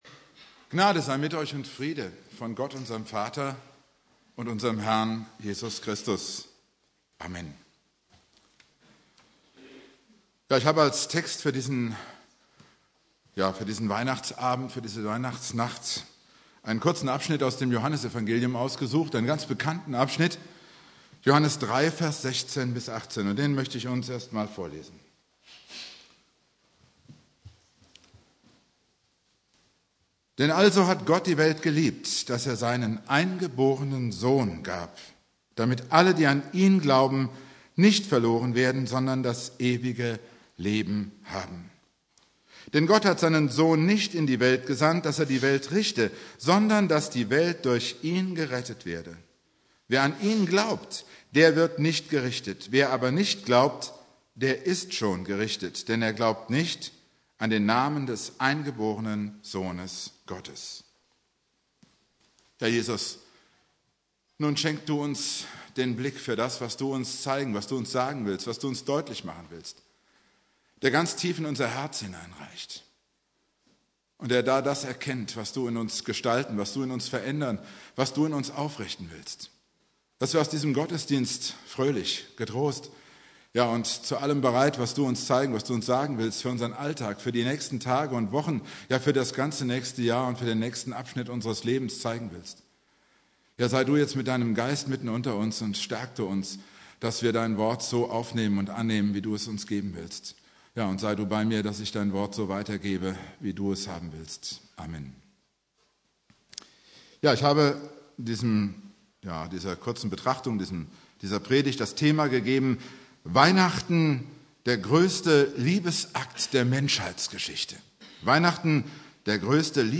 Predigt
Heiligabend